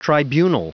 Prononciation du mot tribunal en anglais (fichier audio)
Prononciation du mot : tribunal